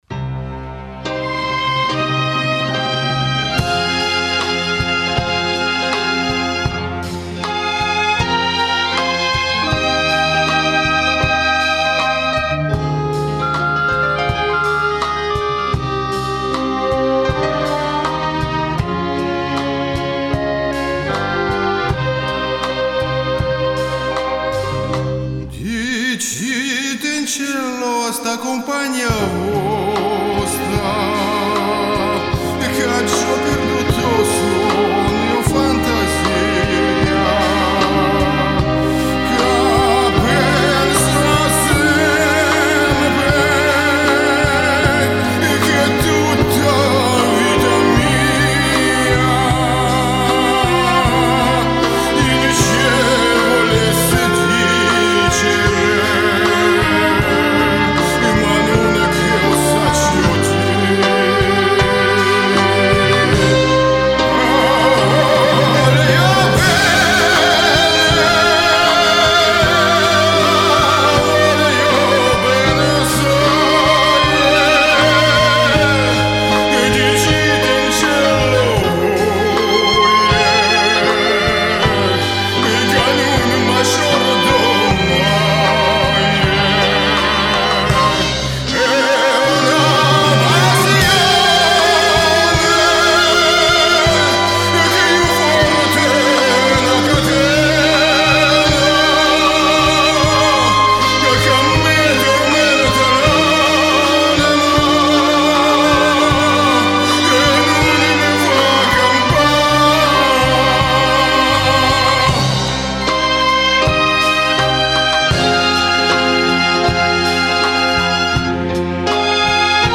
«Неаполитанские песни»
уменьшить уровень сигнала с микрофона и громкость фонограммы (она так орет, что приходится поднимать уровень с микрофона до хрипоты).